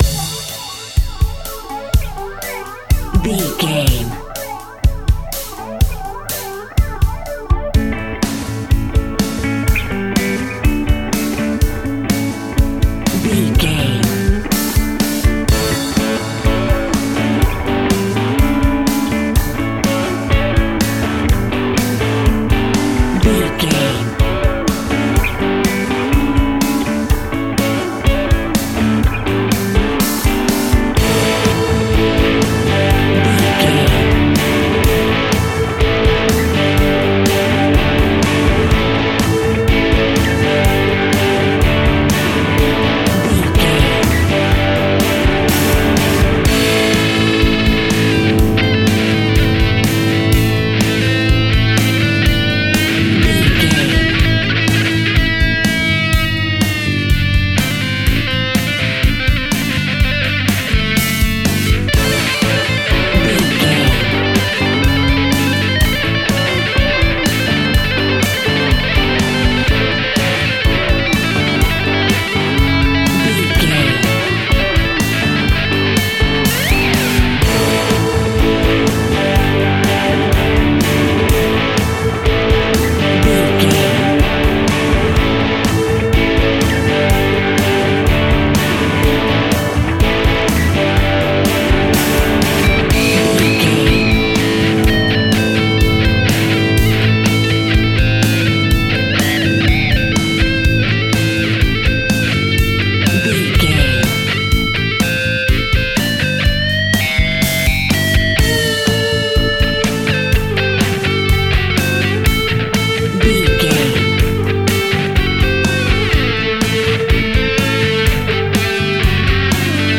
Uplifting
Mixolydian
rock
hard rock
heavy metal
blues rock
distortion
instrumentals
rock guitars
Rock Bass
heavy drums
distorted guitars
hammond organ